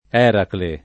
$rakle] pers. m. mit. (= Ercole) e stor. — rara l’accentaz. er#kle, teoricam. possibile nel verso latino: Né i mercatanti di Tiro Nel segno d’Eràcle [n% i merkat#nti di t&ro nel S%n’n’o d er#kle] (D’Annunzio)